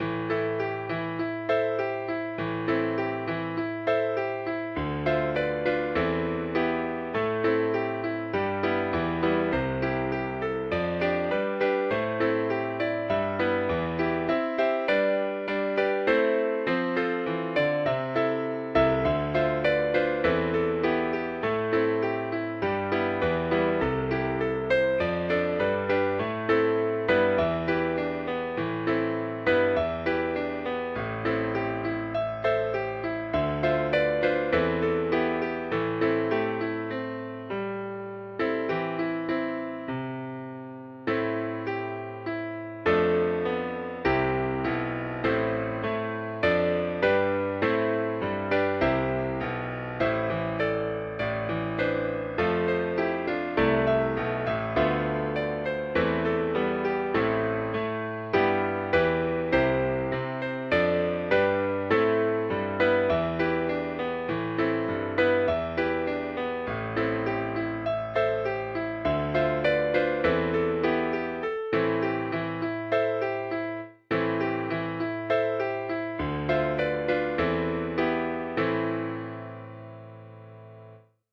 Heavy the Beat of the Weary Waves (Old Dirge from the Isle of Mull
The tune is an old air An cronan Muilach .